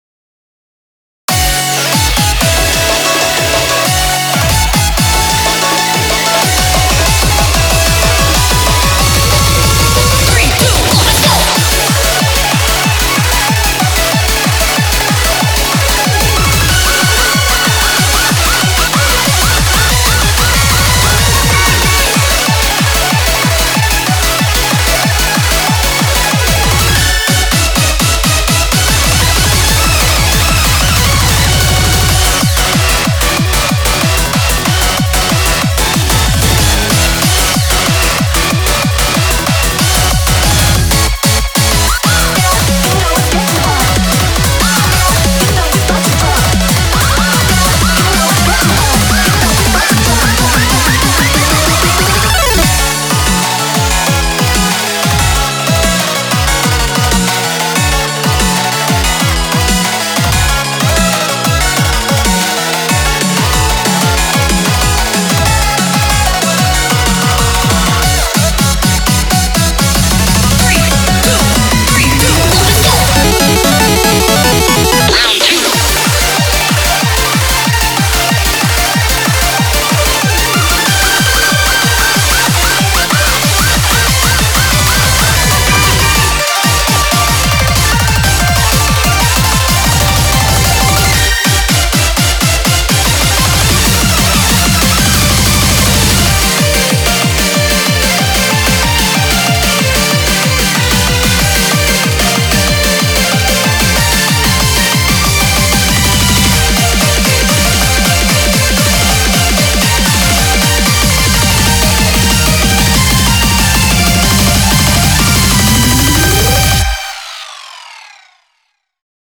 BPM187
Audio QualityPerfect (High Quality)
This is a really strong and energetic techno song
This song just really gets me going, it's pure energy.